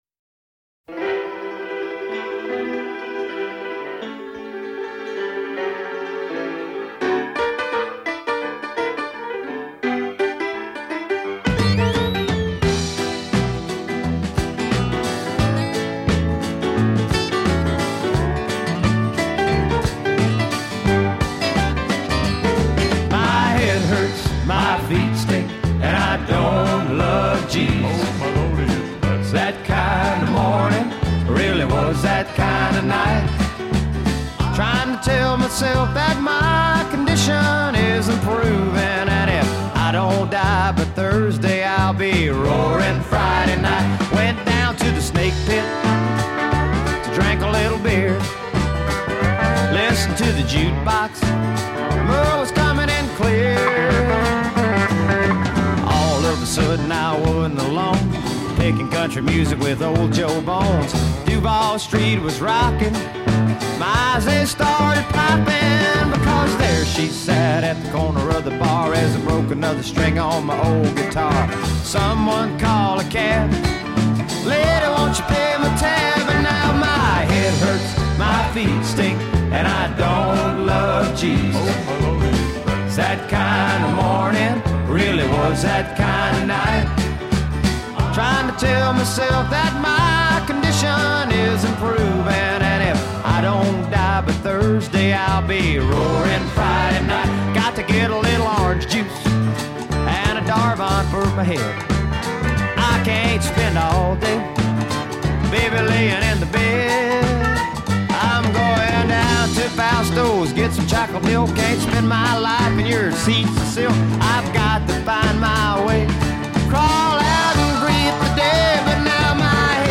an uplifting Gospel tune
Classic Rock